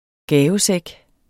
Udtale [ ˈgæːvəˌsεg ]